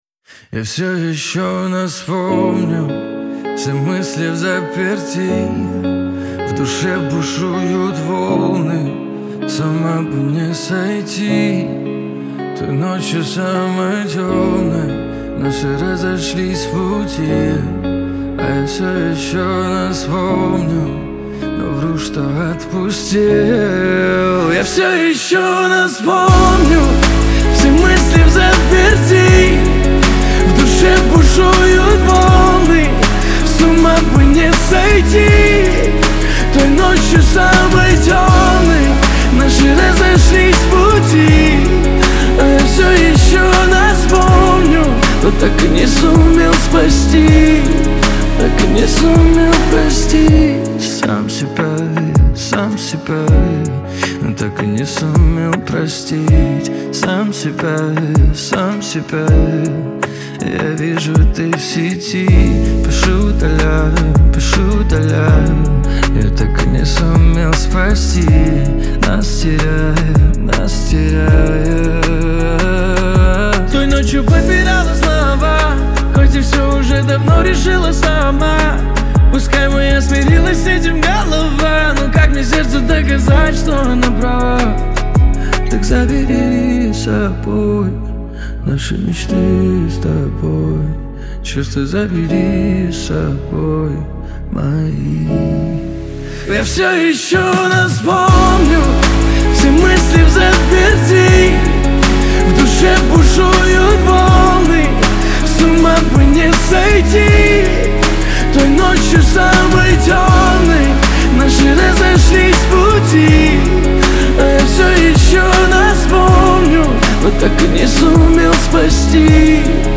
ХАУС-РЭП
грусть , эстрада